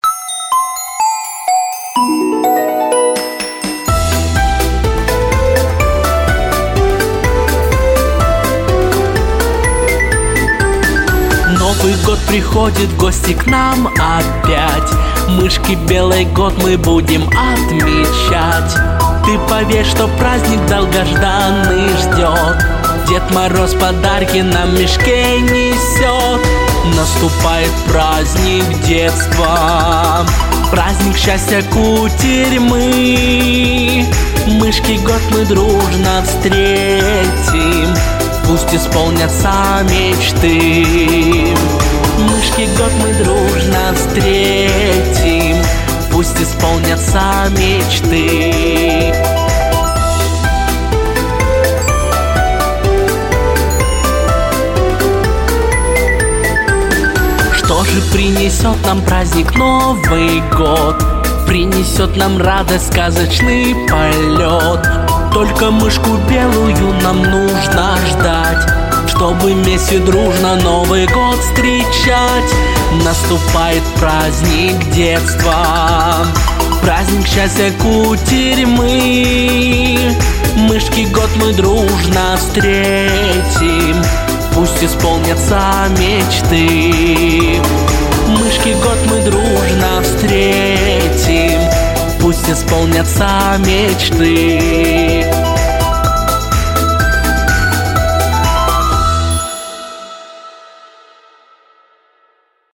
Детские песни / Песни на Новый год 🎄